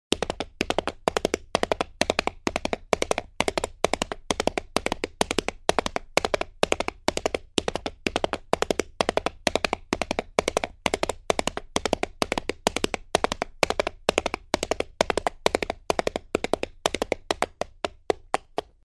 На этой странице собрана коллекция натуральных звуков лошадей.
2. Лошадь мчится по асфальту